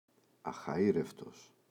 αχαΐρευτος [axa’ireftos] – ΔΠΗ